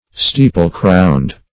Steeple-crowned \Stee"ple-crowned`\ (-kround`), a.